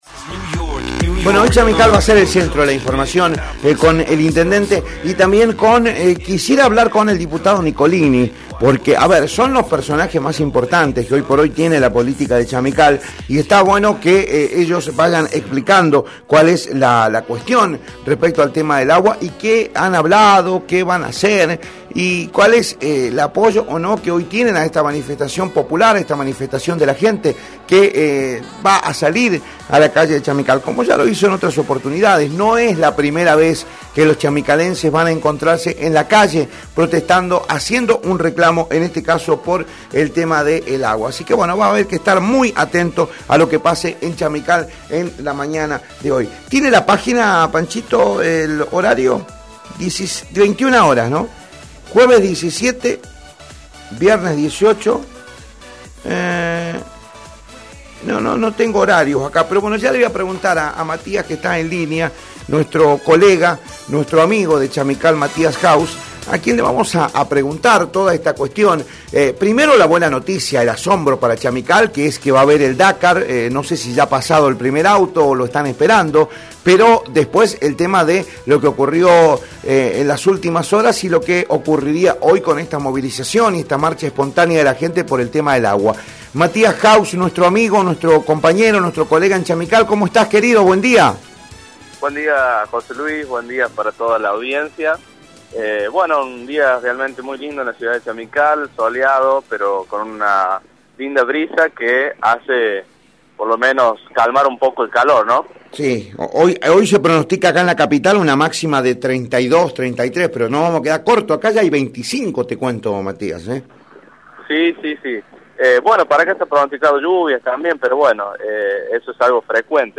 Informe de un periodista de Radio Rioja
01-corresponsal-chamical-faltante-agua-y-movilizacion.mp3